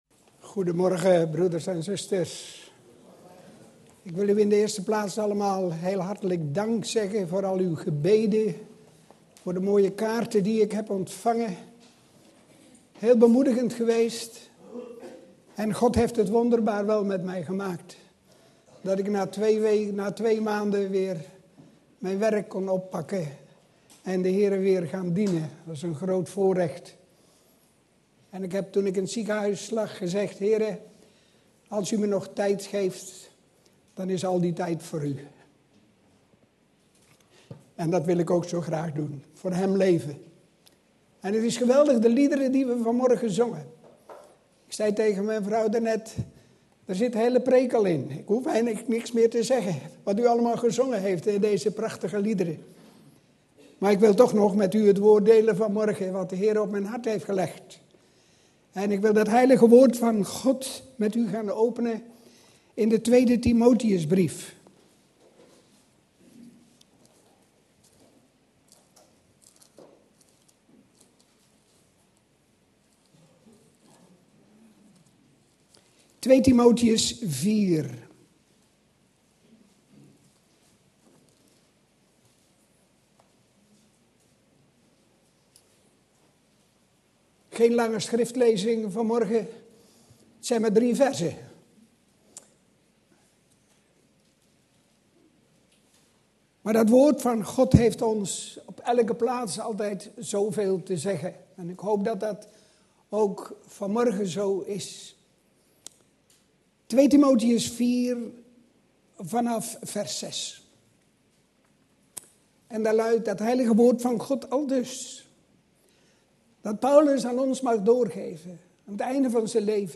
Op ongeveer 23 minuten zit er een storing in het geluid.